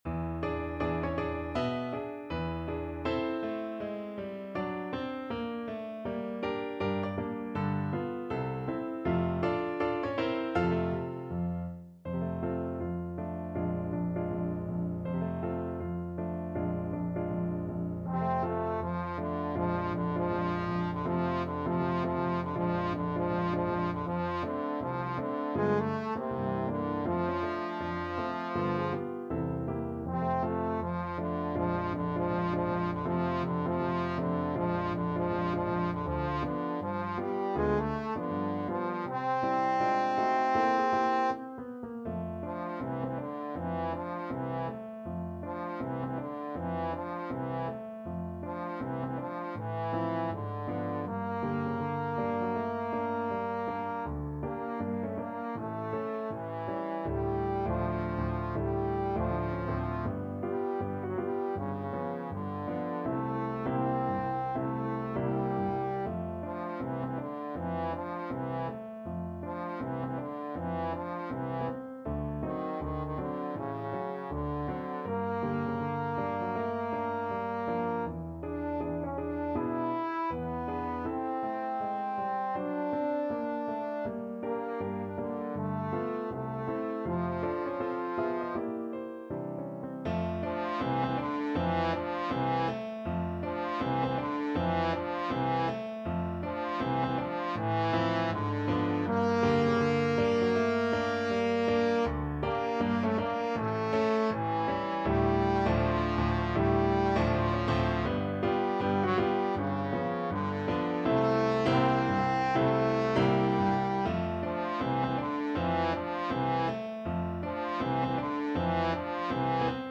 Jazz
~ = 160 Moderato
2/2 (View more 2/2 Music)